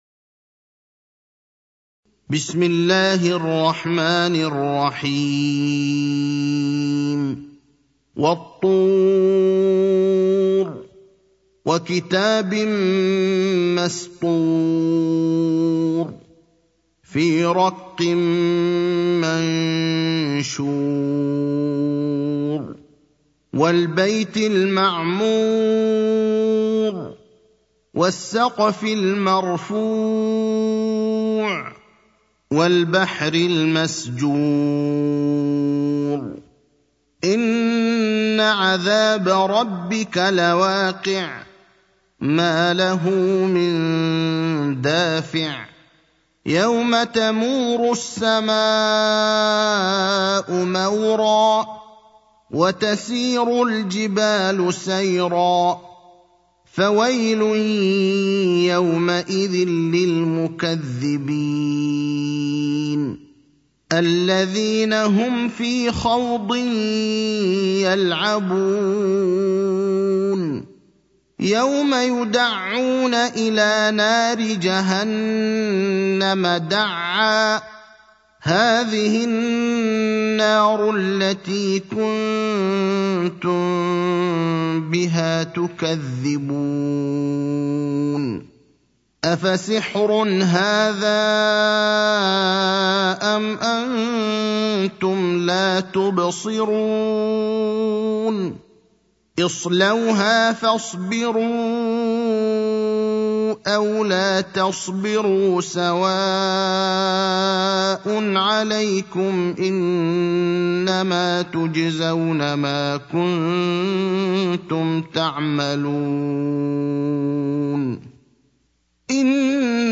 المكان: المسجد النبوي الشيخ: فضيلة الشيخ إبراهيم الأخضر فضيلة الشيخ إبراهيم الأخضر سورة الطور